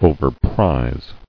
[o·ver·prize]